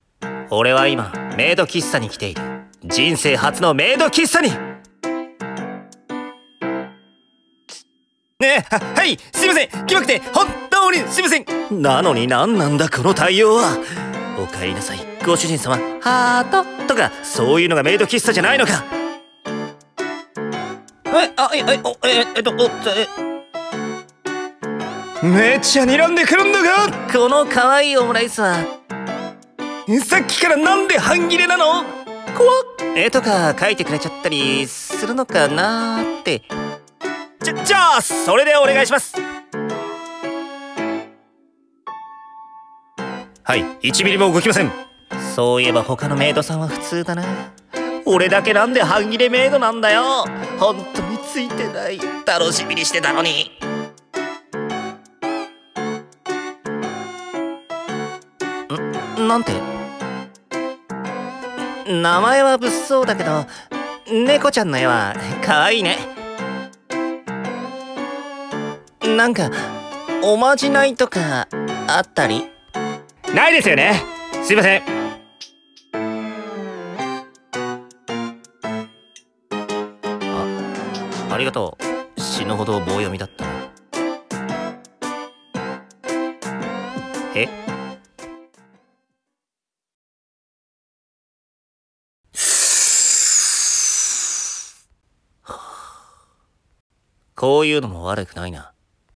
【二人声劇】